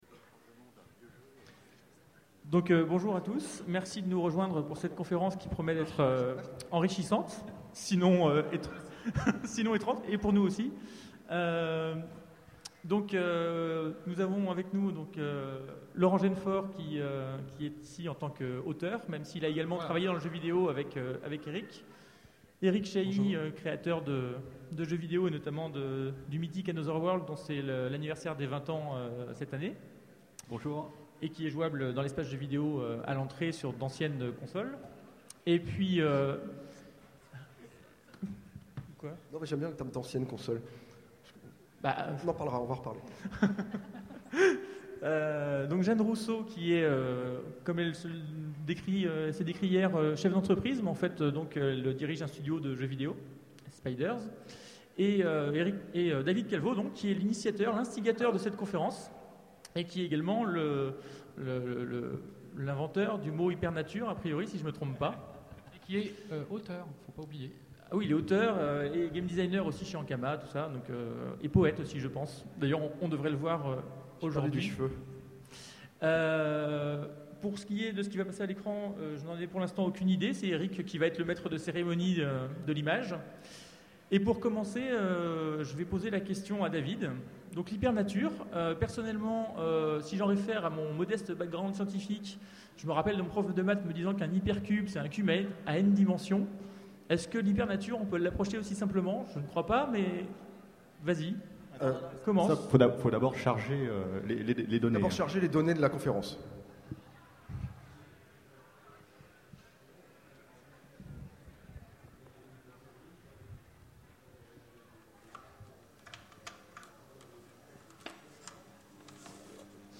Utopiales 2011 : Conférence Hypernature